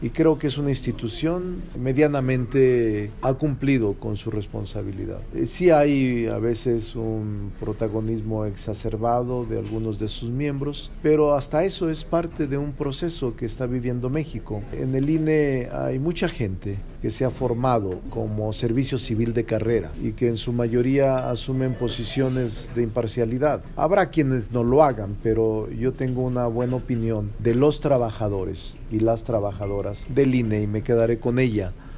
En entrevista, el presidente de la Junta de Coordinación Política, aseveró que algunos consejeros del Instituto Nacional Electoral (INE) tienen un protagonismo exacerbado.